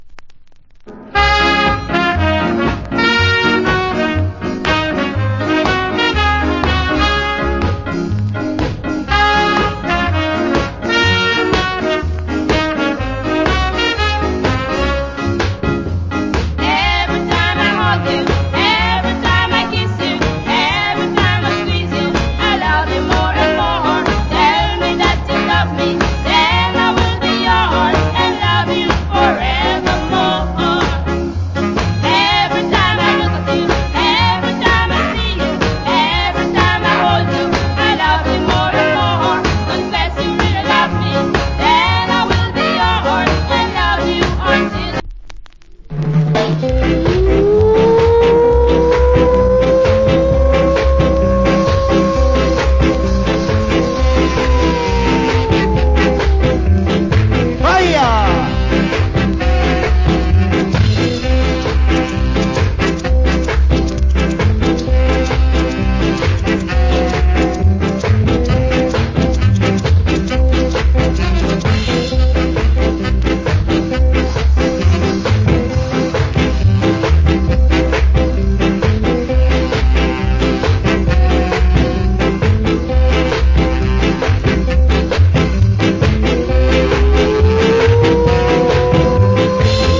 Nice Female Ska Vocal.